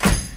Snare (Fire).wav